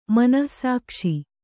♪ manah sākṣi